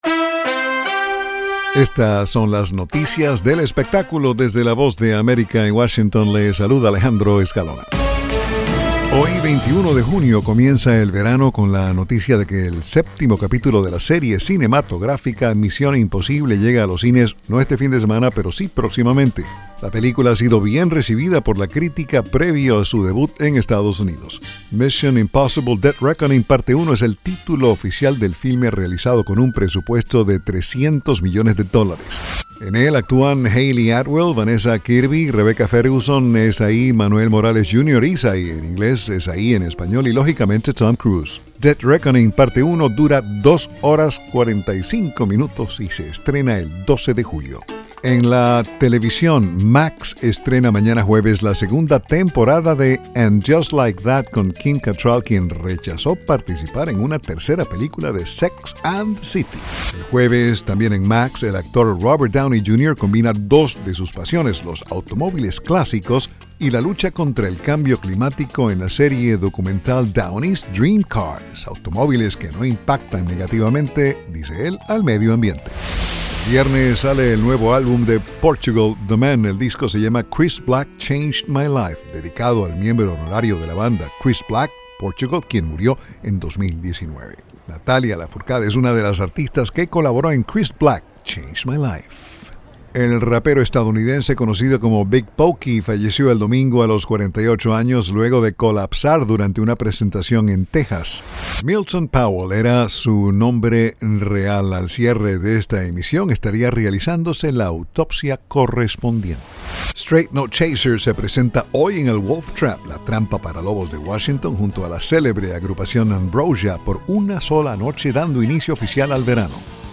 Son las noticias del mundo del espectáculo